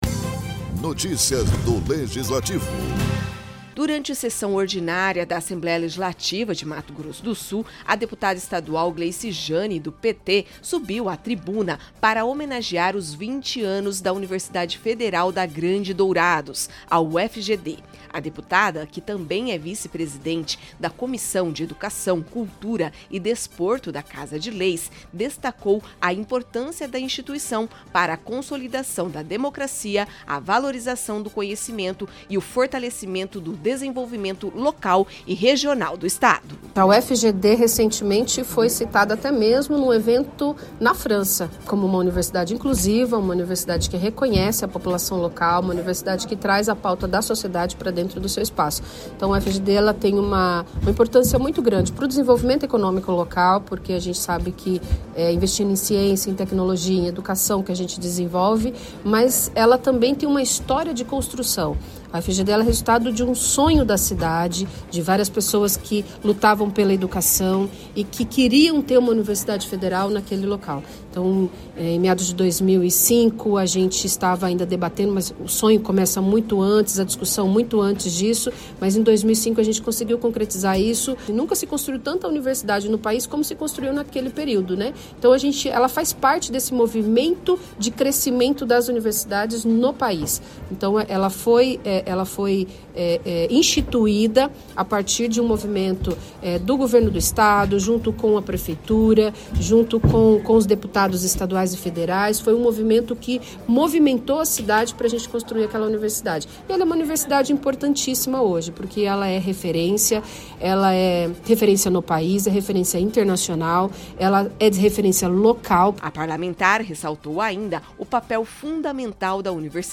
A deputada estadual Gleice Jane (PT) usou a tribuna da Assembleia Legislativa de Mato Grosso do Sul (ALEMS) para homenagear os 20 anos da UFGD, destacando o papel da universidade na construção da democracia e no desenvolvimento regional.